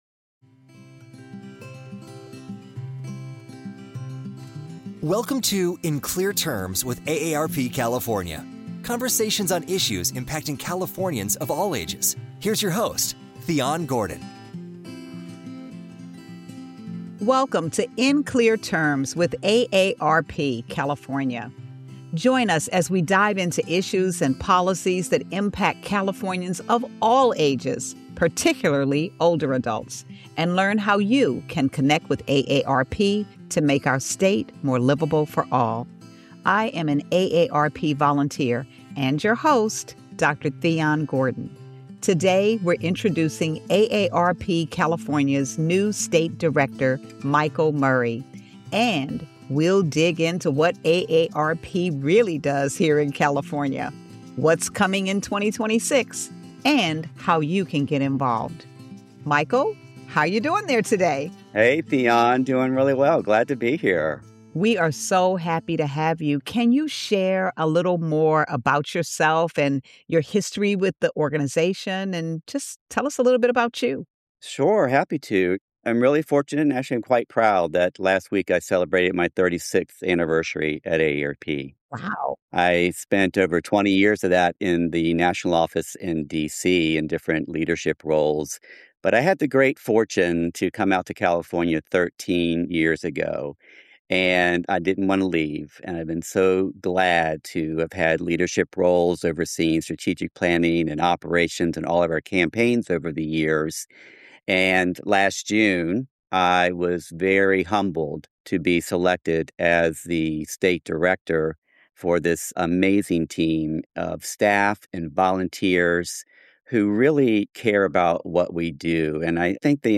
conversations that are fun and thought provoking with community leaders, thought leaders, elected officials, AARP staff and volunteers